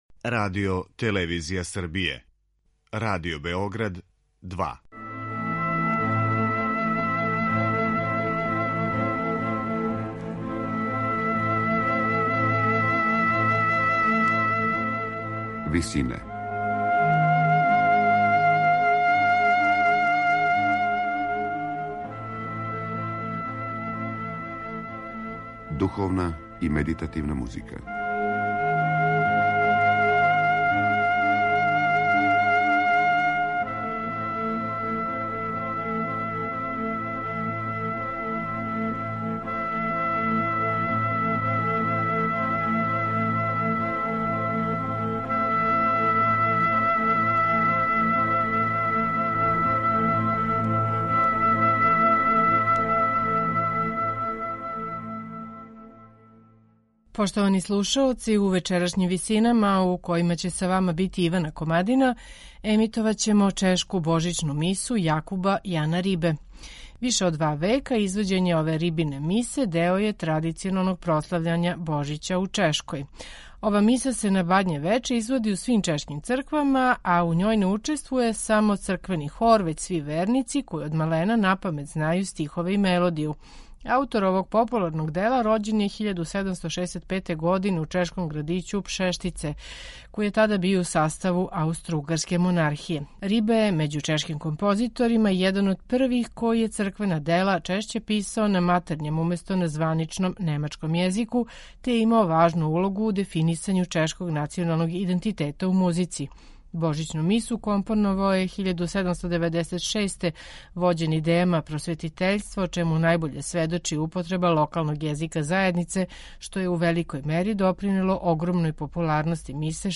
сопран, Магдалена Кожена, мецосопран
тенор
бас
свира на оригиналним инструментима епохе